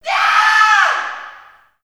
CC - Kanye Shout.wav